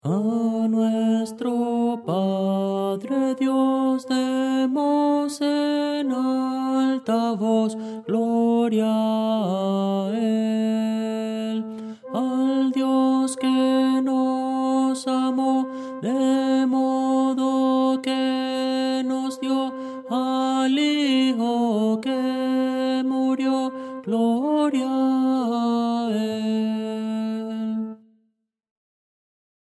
Voces para coro